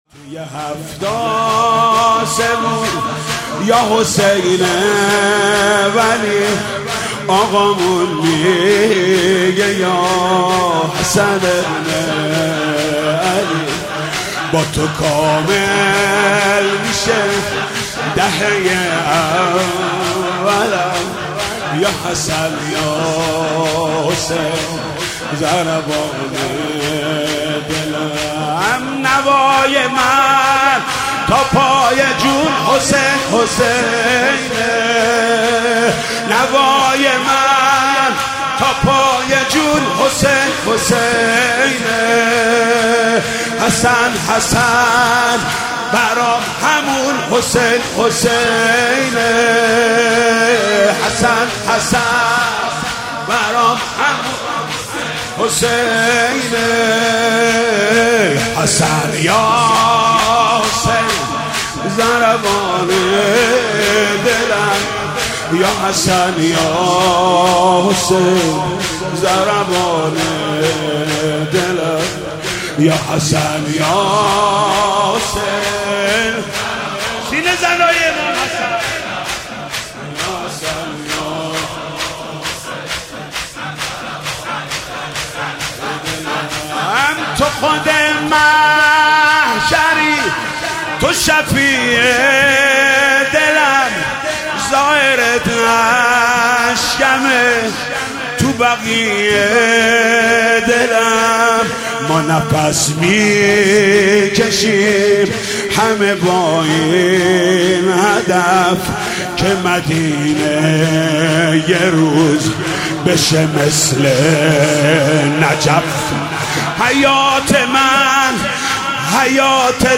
نوحه
مداحی زیبا و احساسی